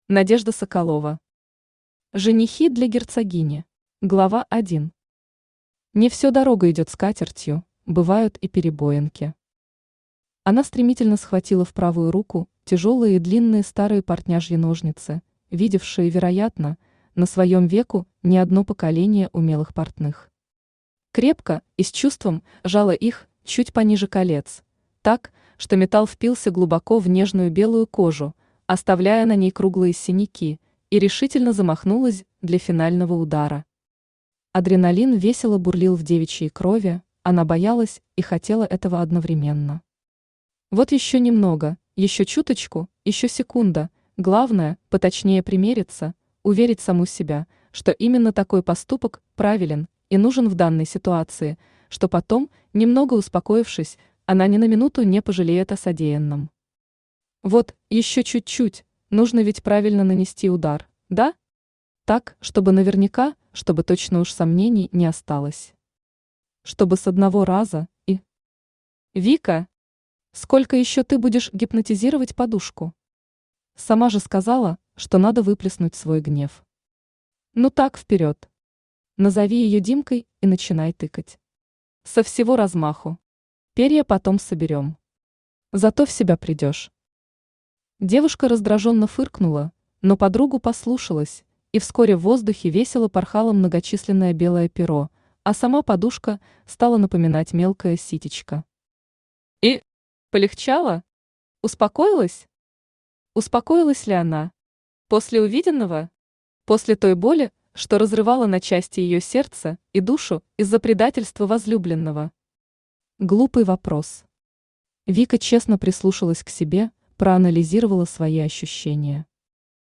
Аудиокнига Женихи для герцогини | Библиотека аудиокниг
Aудиокнига Женихи для герцогини Автор Надежда Игоревна Соколова Читает аудиокнигу Авточтец ЛитРес.